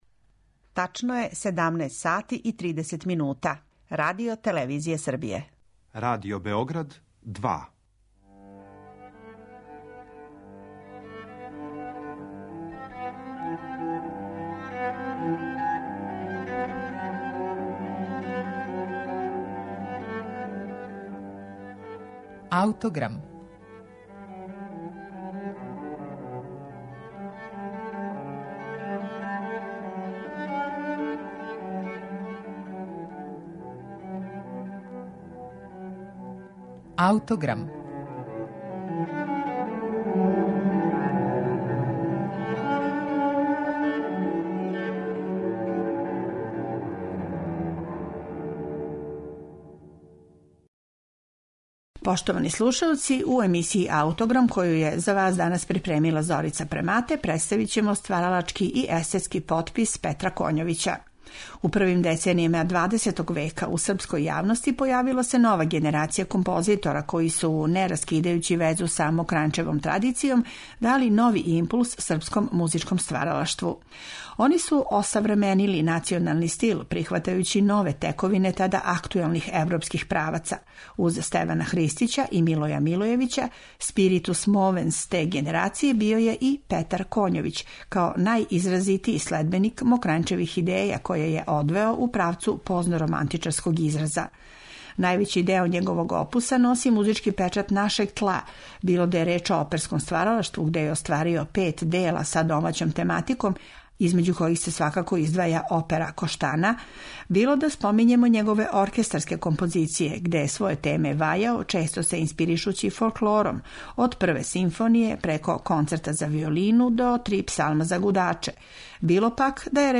Овога пута слушаћете гудачке квартете једног од бардова српске музике прве половине прошлог века Петра Коњовића.